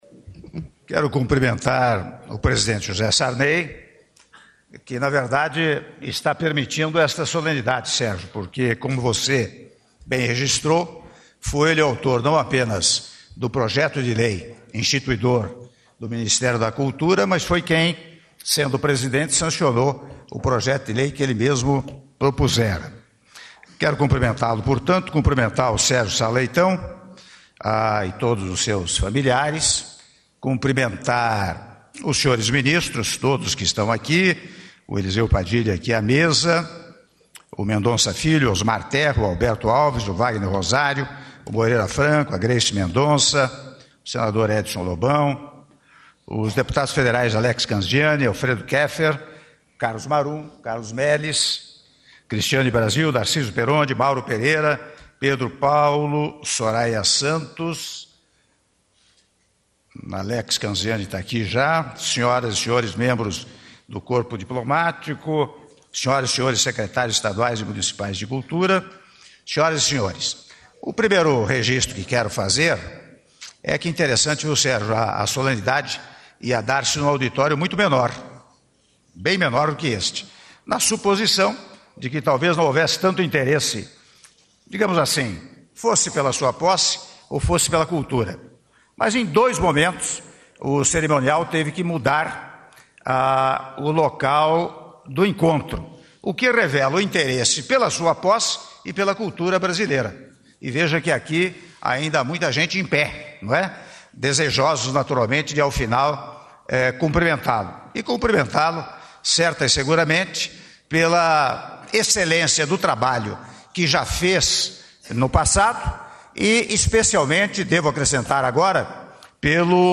Áudio do discurso do Presidente da República, Michel Temer, durante cerimônia de posse do Ministro da Cultura, Sérgio Sá Leitão - Brasília/DF - (08min28s)